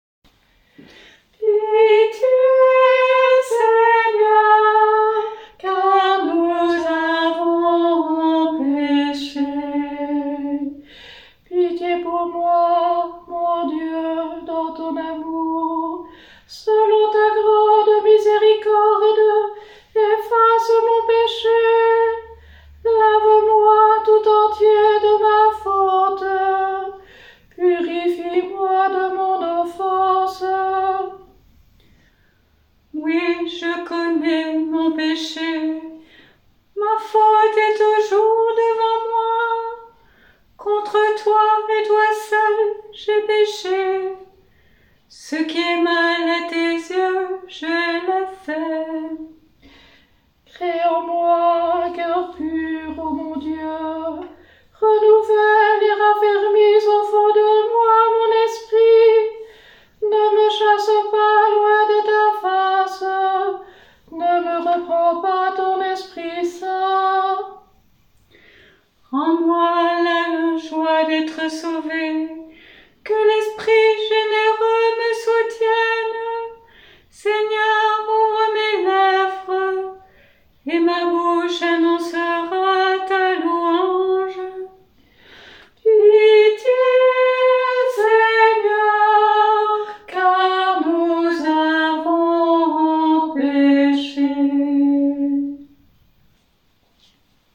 Vous trouverez ci-dessous les enregistrements de ces psaumes, avec les musiques de différents compositeurs, et des mises en œuvre sur 2 stiques (le verset est chanté sur une intonation de deux lignes) ou 4 stiques (le verset est chanté sur une intonation de quatre lignes)
Mercredi des cendres : Psaume 50